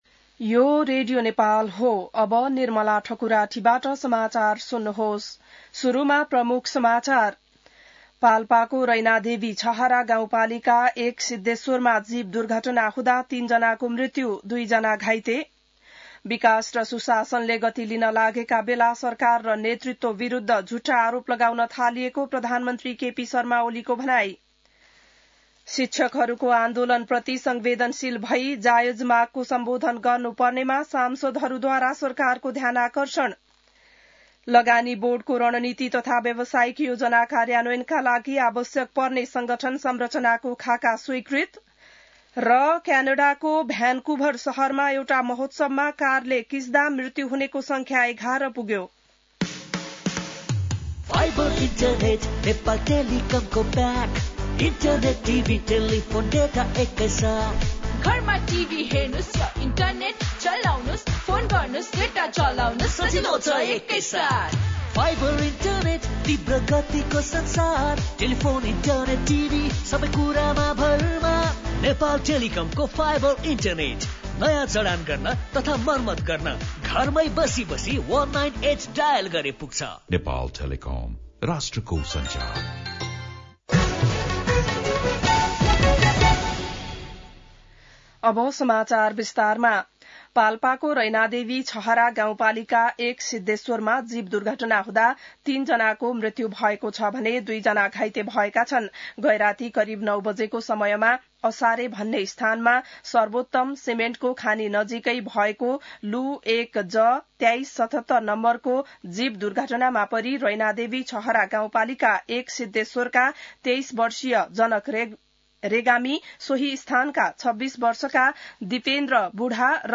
बिहान ७ बजेको नेपाली समाचार : १५ वैशाख , २०८२